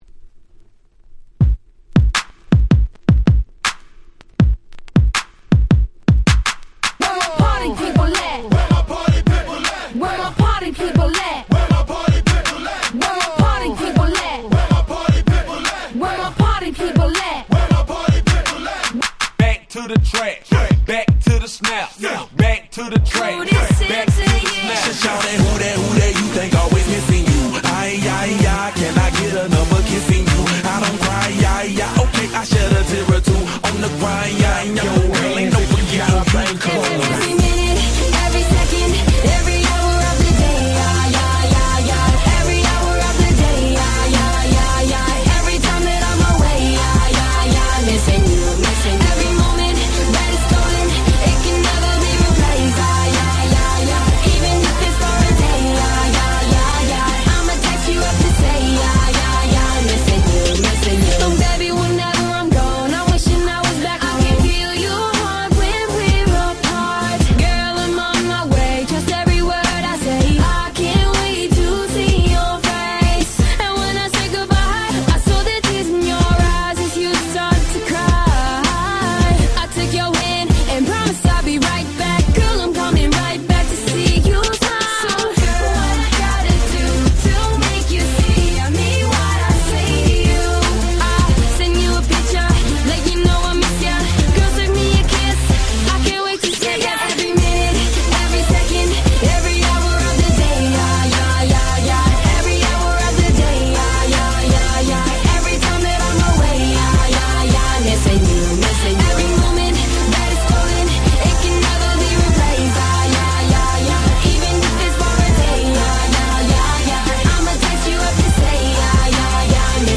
フローライダ デヴィットゲッタ EDM アゲアゲ パリピ 10's